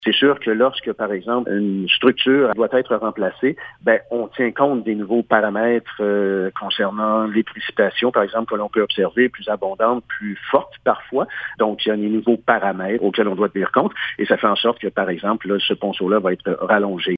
Le porte-parole explique également que la longueur de la nouvelle structure passera de 9 mètres à 12 mètres. L’objectif est de rendre la Véloroute plus résistante aux fortes précipitations qui s’intensifient d’année en année: 13 septembre 2023